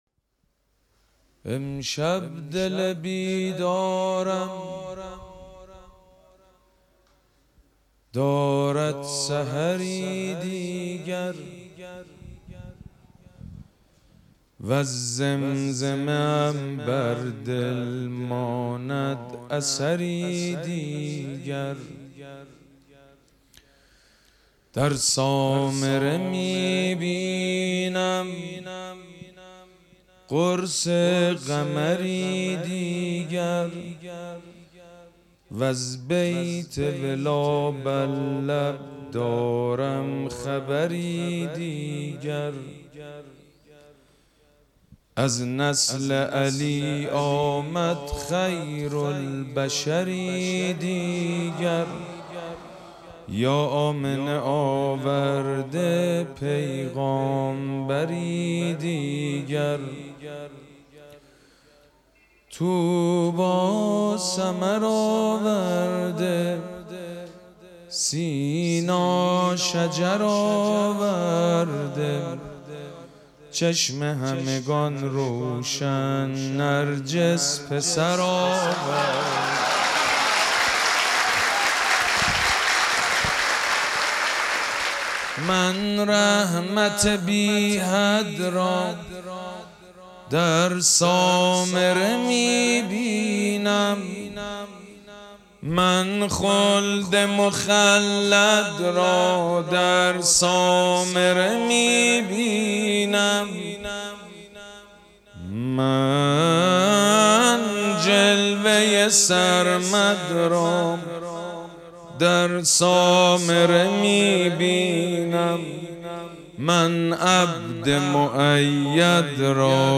مراسم جشن ولادت حضرت صاحب الزمان (عج)
حسینیه ریحانه الحسین سلام الله علیها
مدح